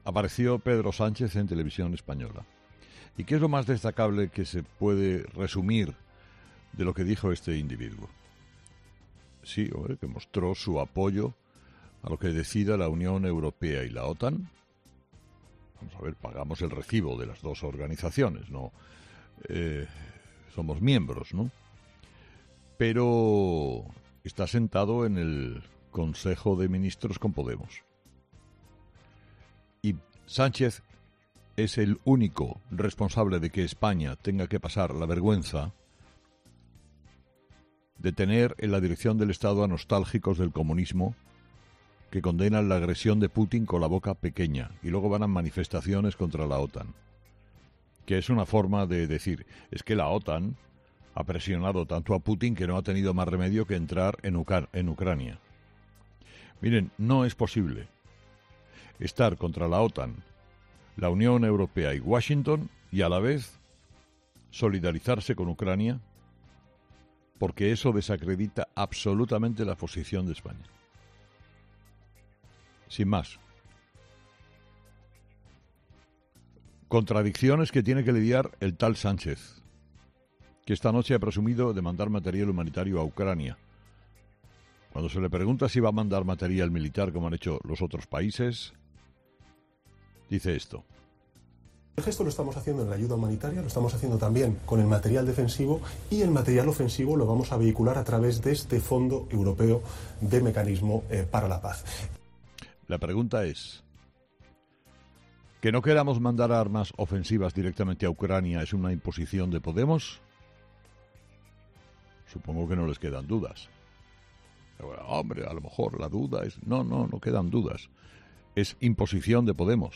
El director de 'Herrera en COPE' ha repasado las principales claves que están marcando la actualidad en territorio ucraniano
Carlos Herrera, director y presentador de 'Herrera en COPE', ha comenzado el programa de este martes analizando las principales claves de la jornada, que pasan, entre otros asuntos, por la actualidad que otra jornada más deja el conflicto bélico entre Rusia y Ucrania en territorio ucraniano y la Junta Directiva Nacional del Partido Popular.